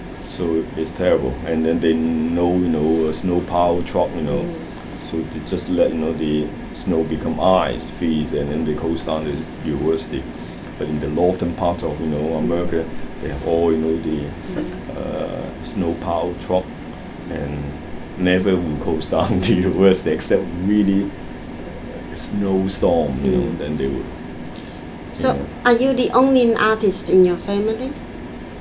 S1 = Taiwanese female S2 = Hong Kong male Context: S2 is talking about the unexpected cold weather that sometimes occurs in northern Texas.
Intended Words : plough , close Heard as : power , goes Discussion : In both these words, there is no [l] after the initial plosive.